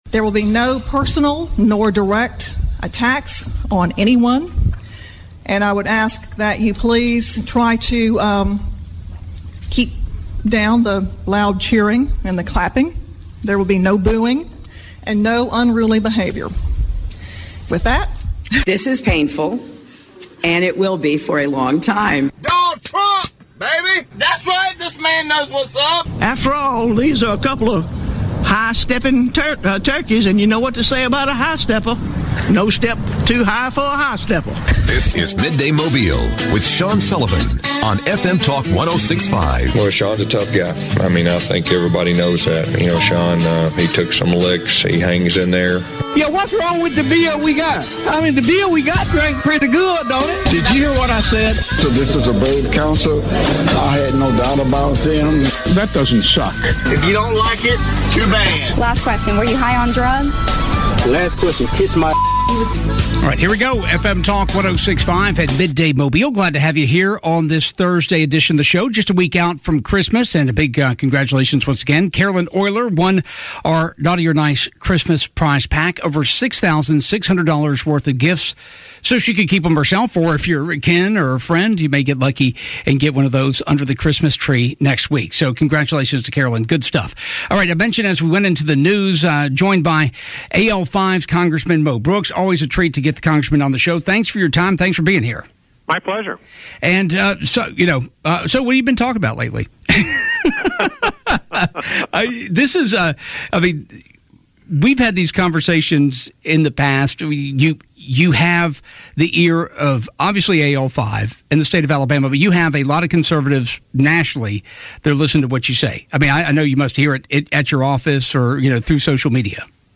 Congressman Mo Brooks discusses his reasoning behind challenging the election of Joe Biden.